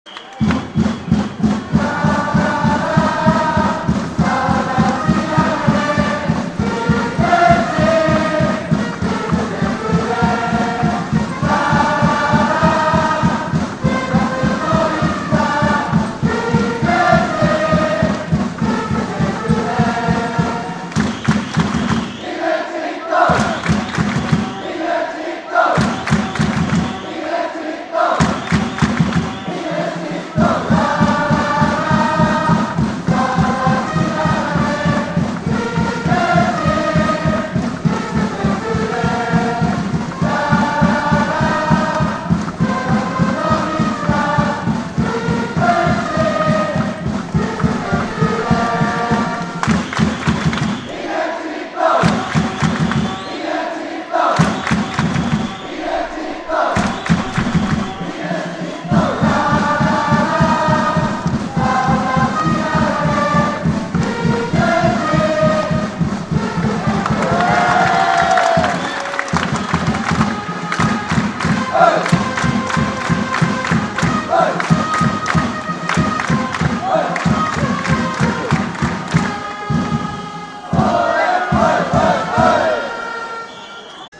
このページは２００９年までの応援歌の記録です。
応援歌 爽やかなイメージです？